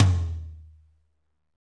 TOM M R M0SR.wav